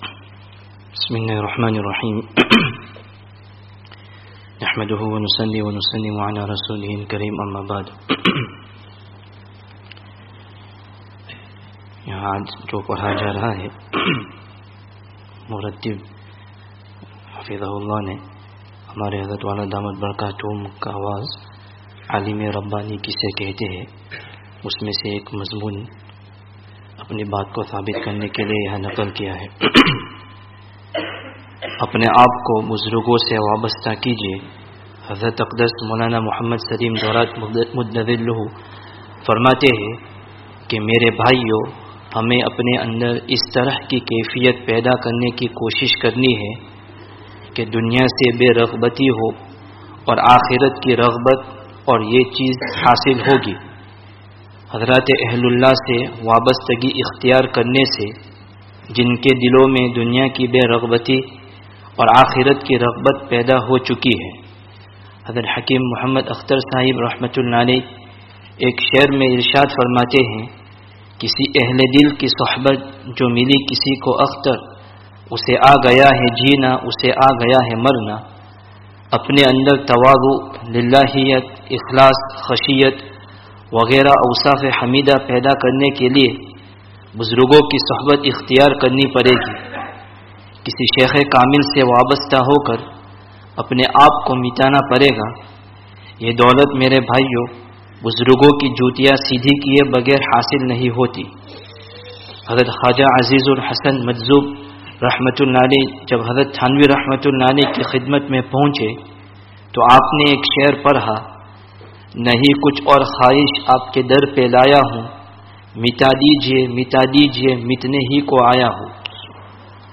[Informal Majlis] Nafs ke Dhokey se Bachney ke liye Nafs ko Dhokā Do (19/10/19)